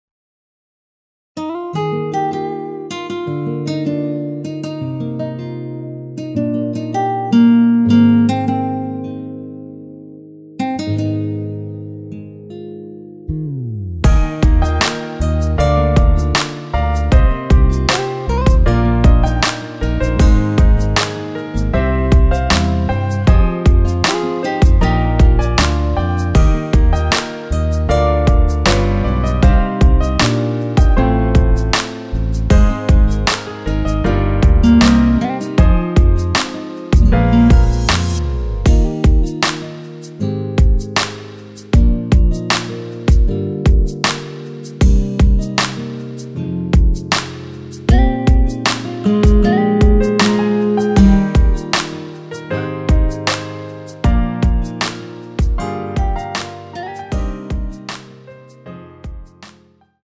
원키에서(-1)내린 MR입니다.
Eb
앞부분30초, 뒷부분30초씩 편집해서 올려 드리고 있습니다.